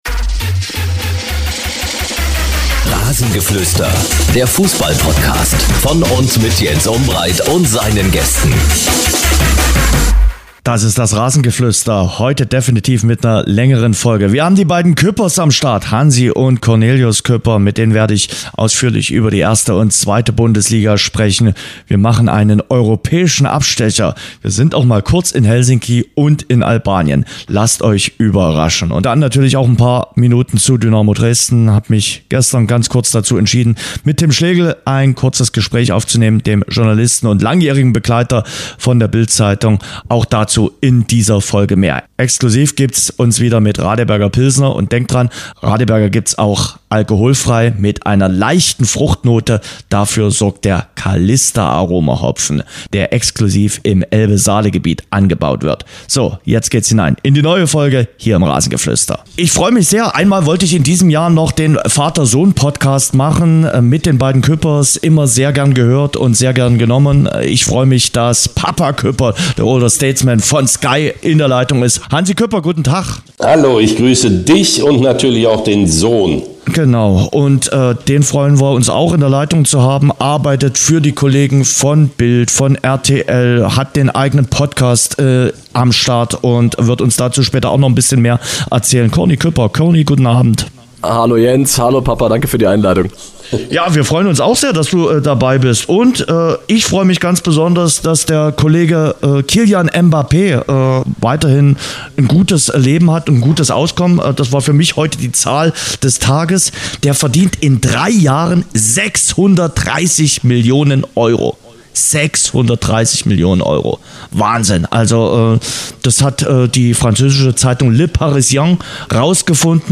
Dazu ein Interview